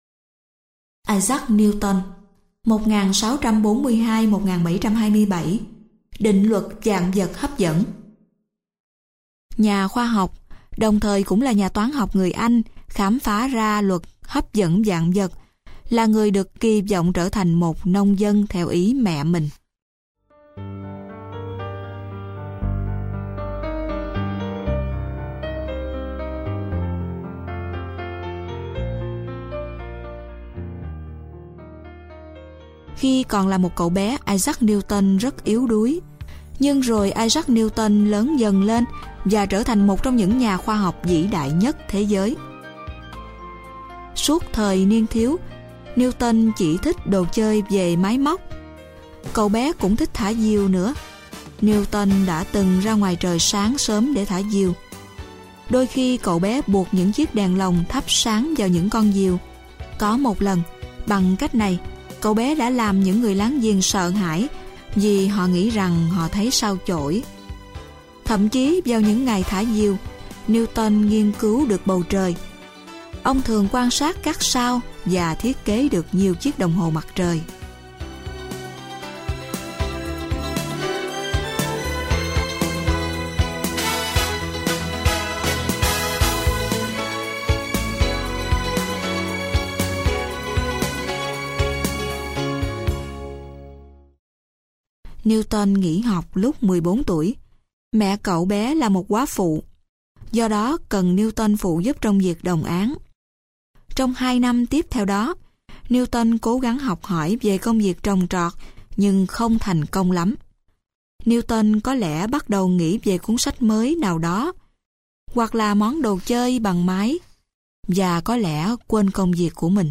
Sách nói Các Nhà Khoa Học Và Những Phát Minh - Sách Nói Online Hay